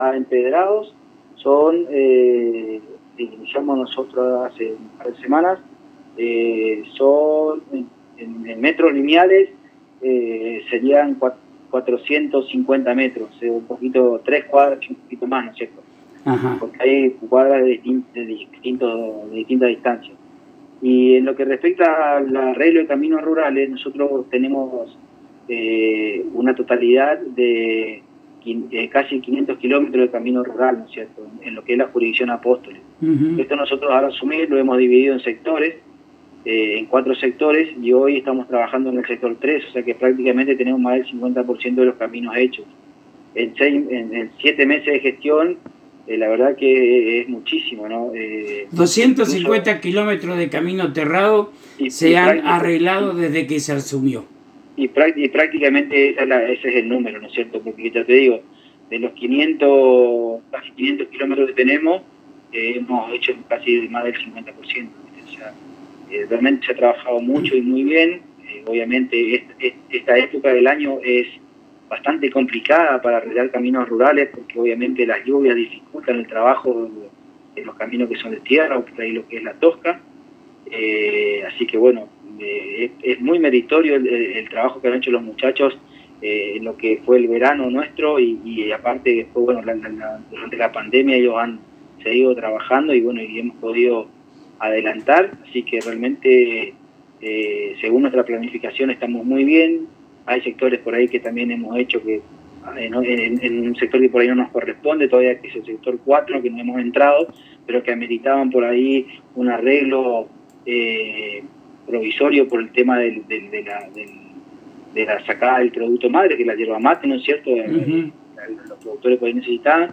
Audio Secretario Obras Públicas Juanji Ferreyra